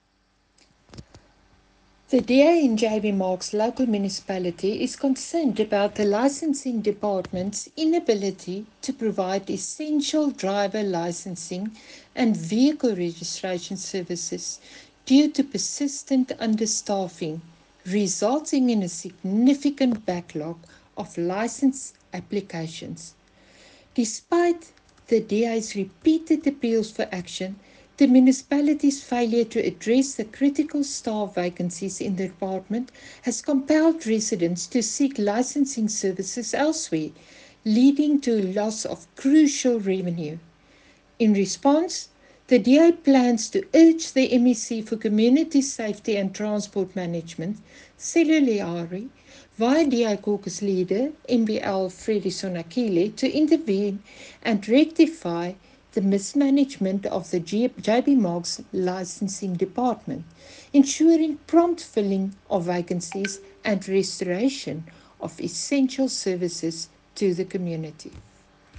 Note to Broadcasters: Please find linked soundbites in
Cllr-Jeanne-Adriaanse-License-Dept-Eng.mp3